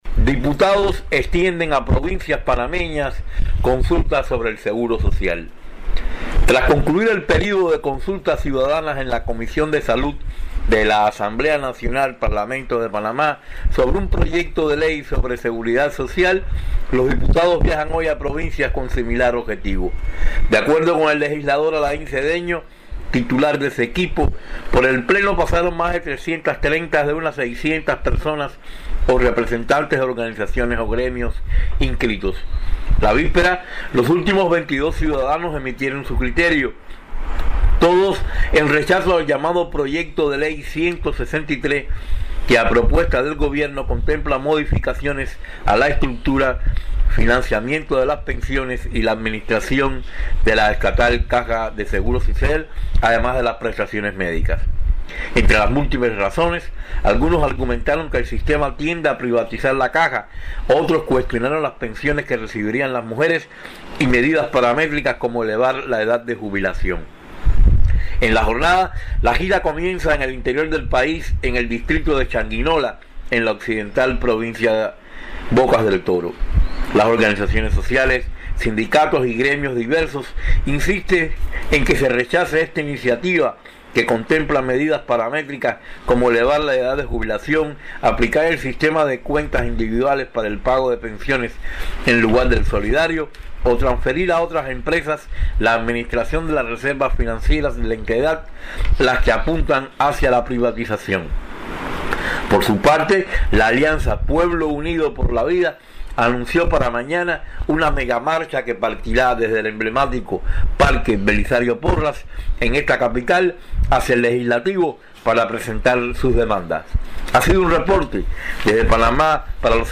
desde Ciudad de Panamá